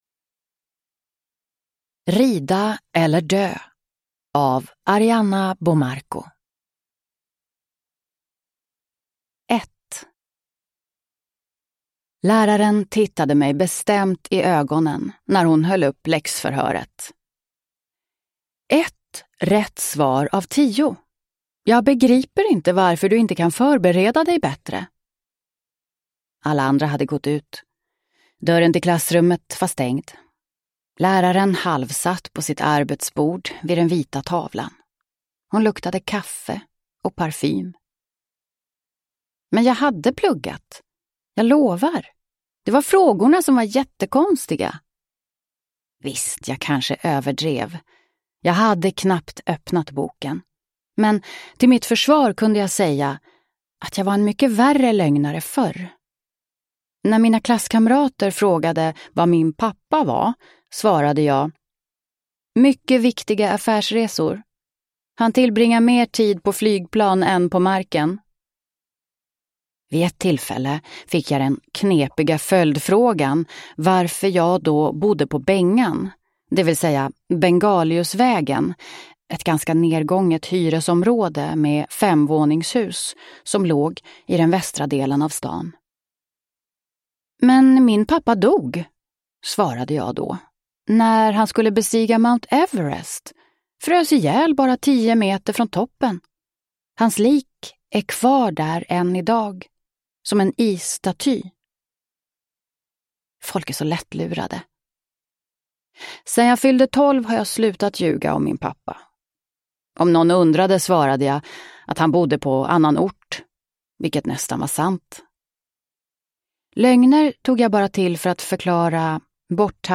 Rida eller dö – Ljudbok